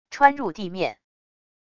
穿入地面wav音频